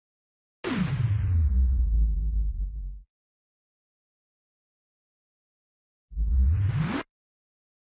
دانلود آهنگ صحنه آهسته 2 از افکت صوتی طبیعت و محیط
جلوه های صوتی
برچسب: دانلود آهنگ های افکت صوتی طبیعت و محیط دانلود آلبوم صدای صحنه آهسته یا اسلو موشن از افکت صوتی طبیعت و محیط